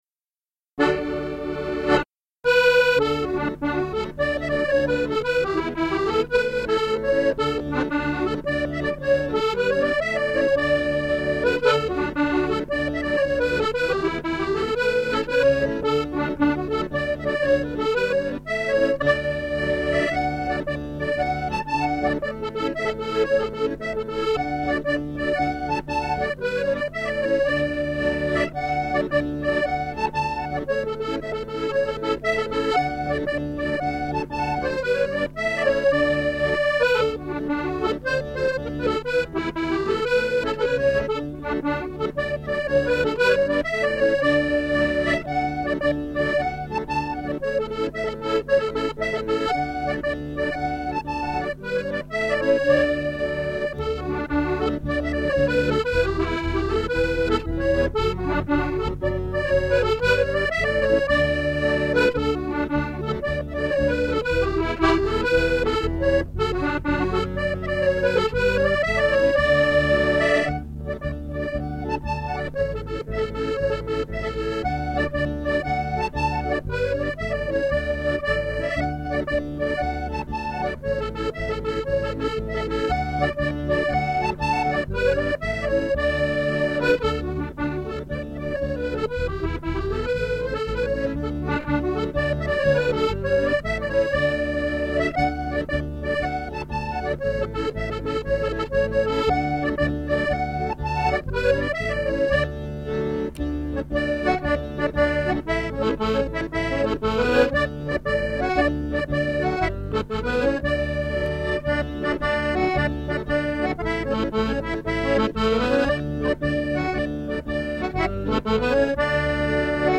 Music - 48 bar jigs or reels